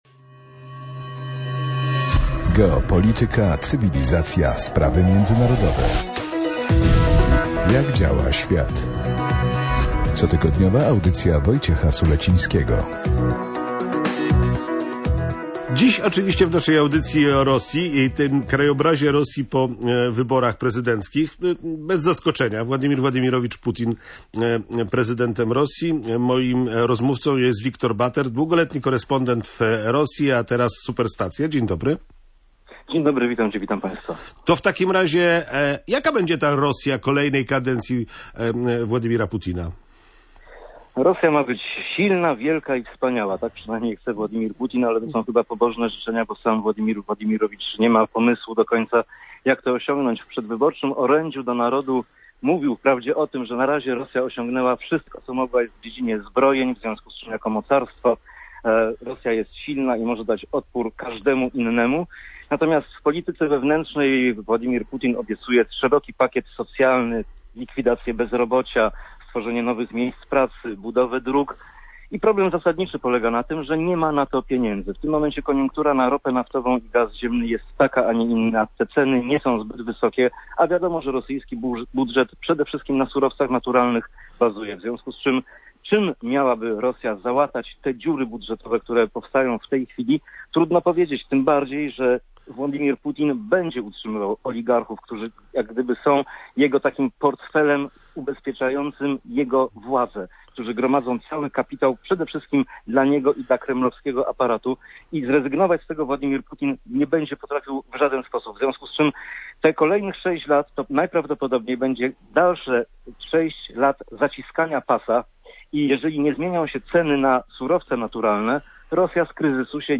O tym, jakie konsekwencje geopolityczne będzie miał triumf Władimira Putina, rozmawiali w audycji Jak